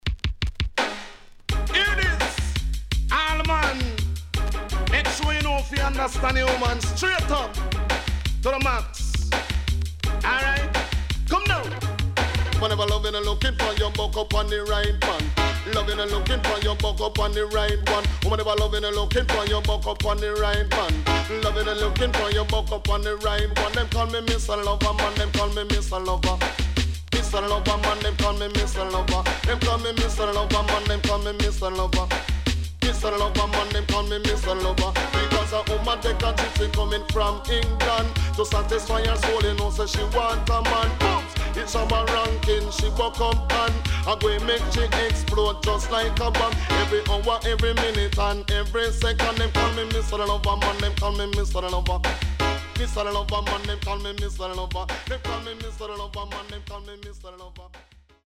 HOME > DANCEHALL  >  COMBINATION
SIDE A:少しチリノイズ入りますが良好です。